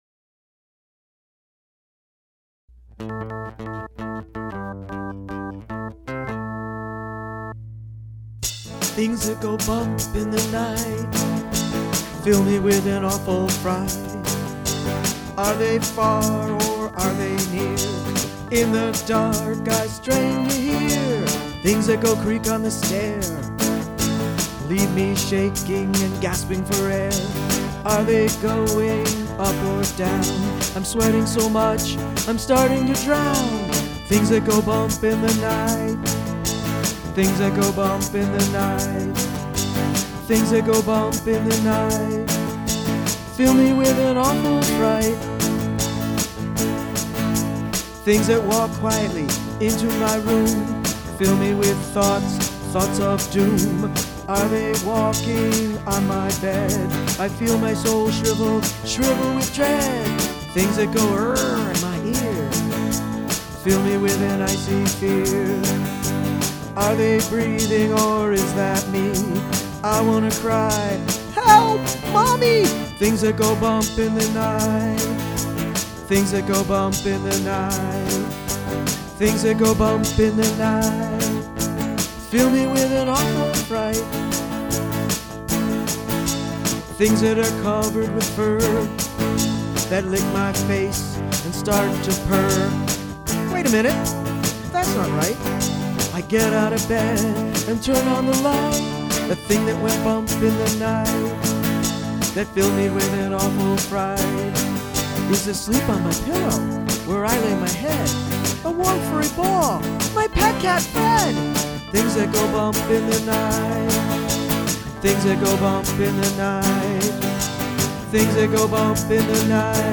Politically Incorrect Modern Blues
Recorded in Low-Fidelity May 2006
Guitar, Vocals, Keyboards
and Drum Machine.